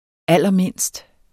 Udtale [ ˈalˀʌˈmenˀsd ]